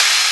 TM88 ShaderOpen-Hat.wav